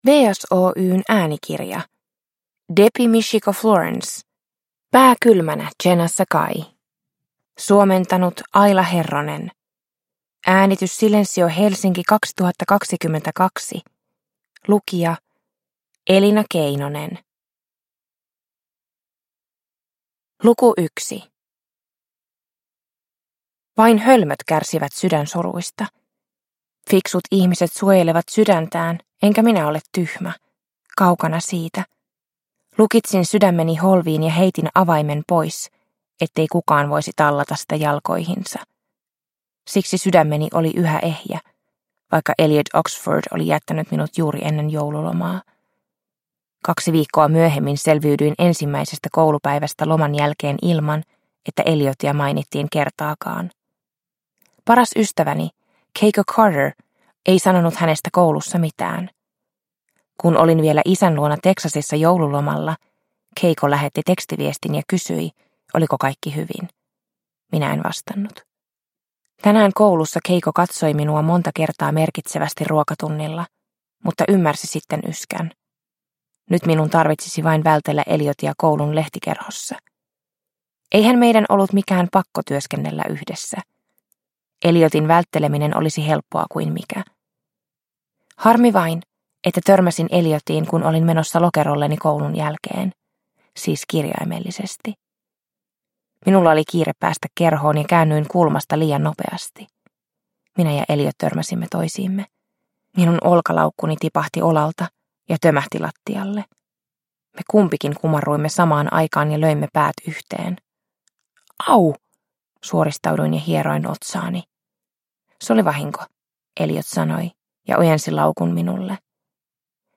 Pää kylmänä, Jenna Sakai – Ljudbok – Laddas ner